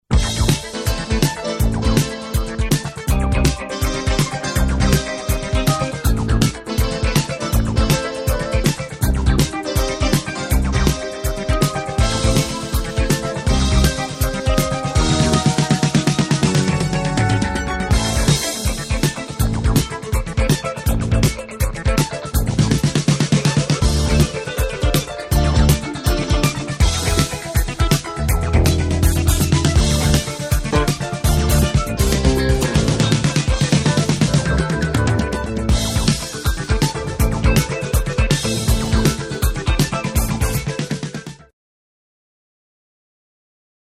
Die dänisch-traurig-lustige Musik, der Schwarze,